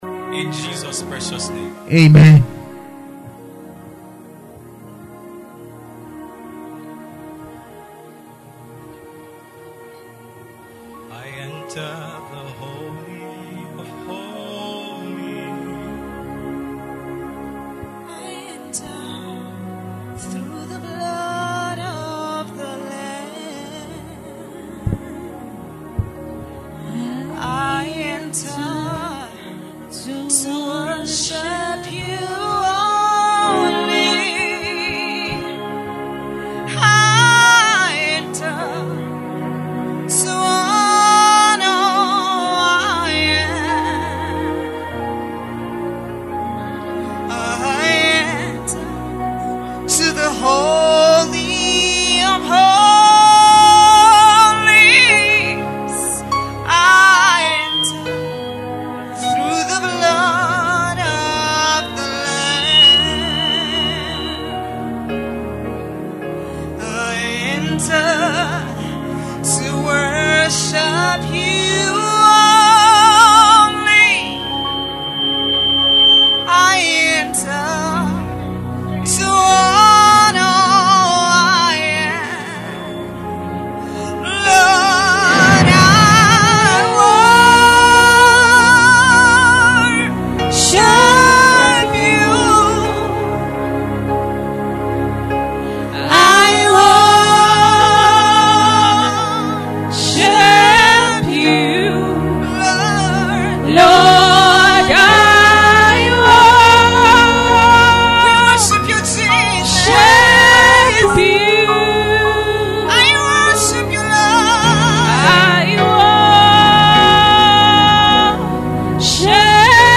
At the Apo Open Air Convergence 2026 the reality of the Kingdom was tangibly felt.
This audio captures the intensity, prayers, impartations, declarations, and divine encounters that marked the gathering.